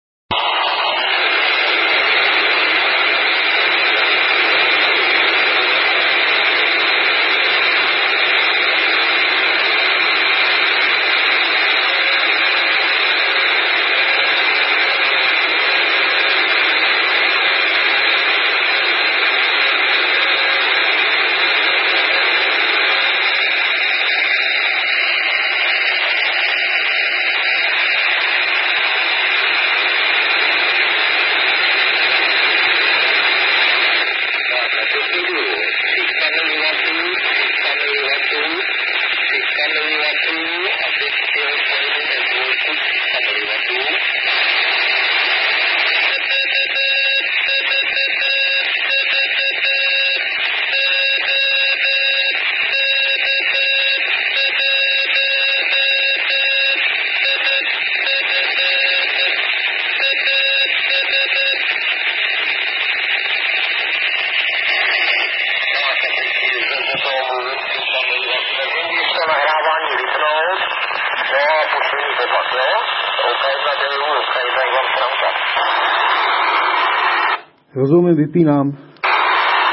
Nejprve jsem fone volal s 50 mW. Na nahrávce v jednom místě je torzo hlasu i slyšitelné. Pak jsem za stejného výkonu zavysílal módem F2.